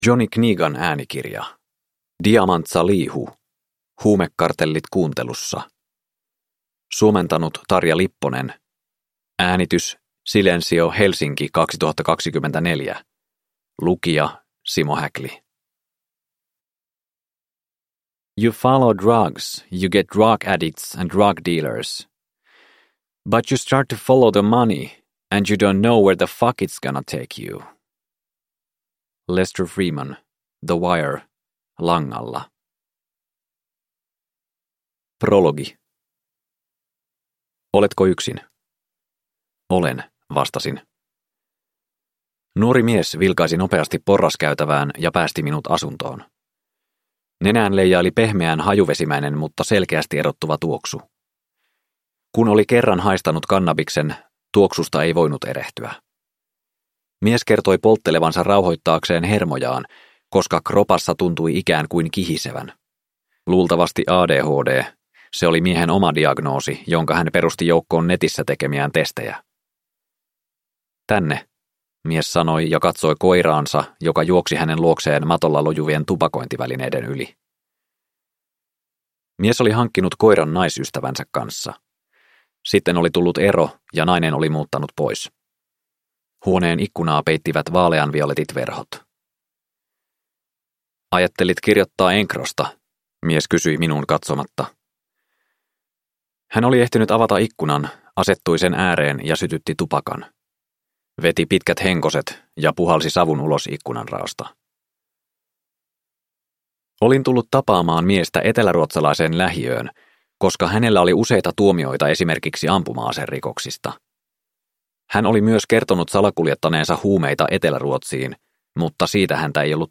Huumekartellit kuuntelussa (ljudbok) av Diamant Salihu